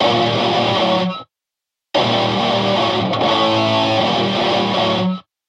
Zystrix Recording Metal Guitar - two guitars.mp3